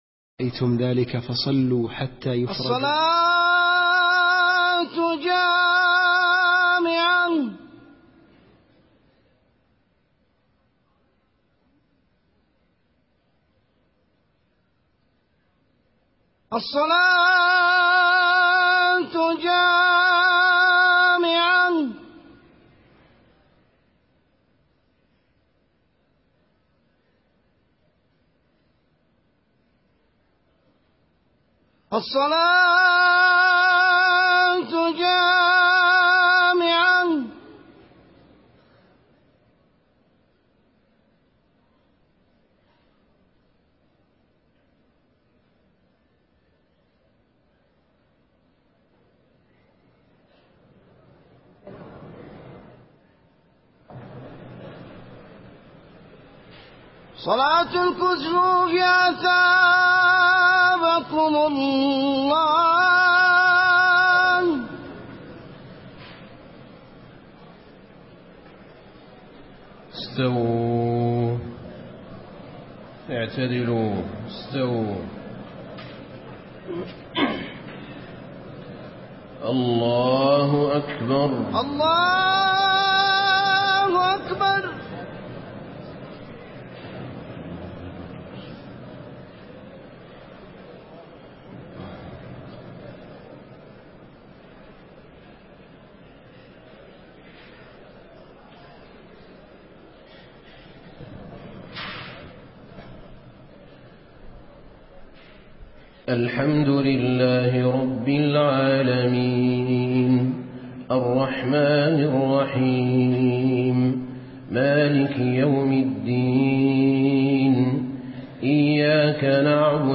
خطبة الكسوف المدينة - الشيخ أحمد بن طالب
تاريخ النشر ٢٩ ذو الحجة ١٤٣٤ هـ المكان: المسجد النبوي الشيخ: فضيلة الشيخ أحمد بن طالب بن حميد فضيلة الشيخ أحمد بن طالب بن حميد خطبة الكسوف المدينة - الشيخ أحمد بن طالب